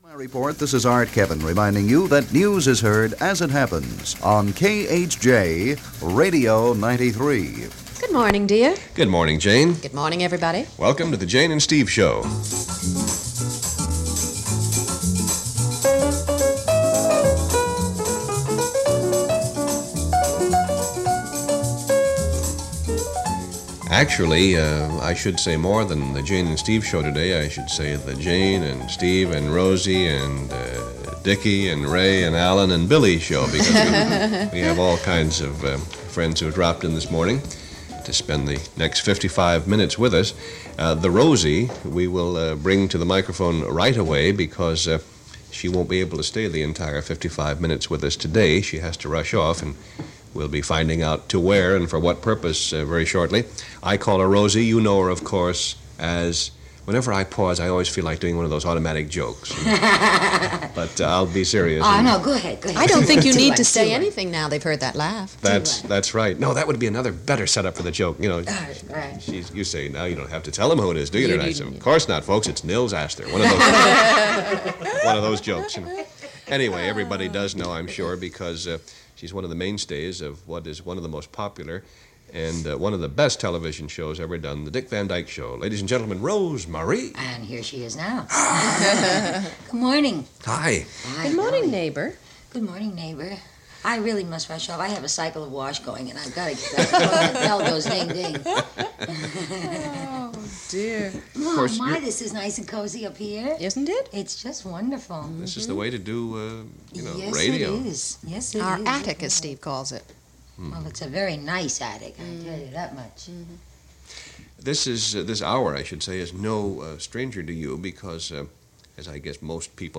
Breakfast with Jayne (Meadows) and Steve (Allen) - 1965 - broadcast live on February 18, 1965 from KHJ AM & FM, Los Angeles.
Interesting to consider, this morning chat show was on a station that would become the flagship for a top-40 revolution only weeks later. But before the plunge into top-40, future Boss Radio airwaves were holding court during most mornings with Jayne Meadows and Steve Allen, all broadcast live from their studios in Encino on February 18, 1965.
Guests on this episode included Rosemarie from The Dick Van Dyke show and Alan Young from Mr. Ed as well as several personalities, who popped in for the sheer gift of gab.